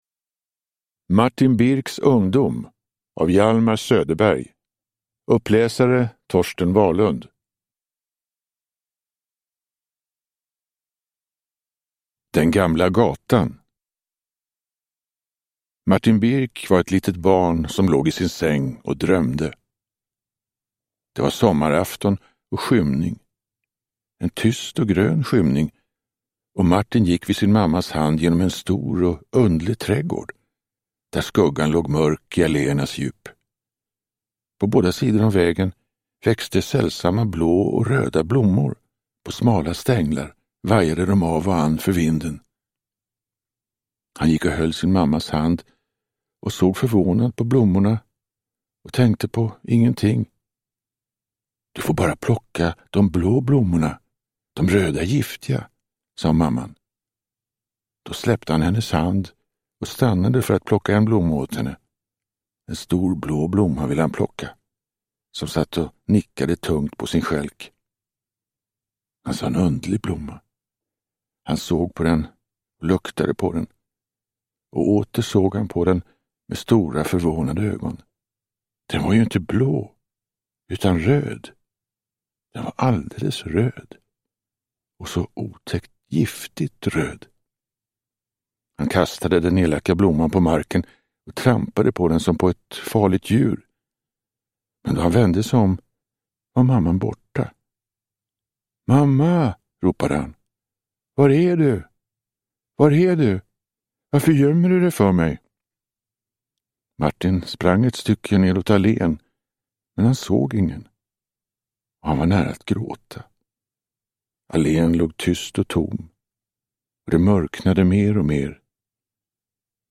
Martin Bircks ungdom – Ljudbok
Uppläsare: Torsten Wahlund